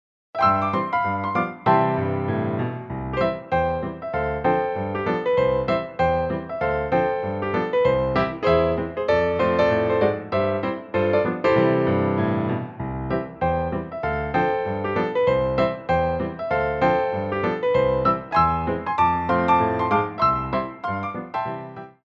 2/4 (16x8) + Stop Time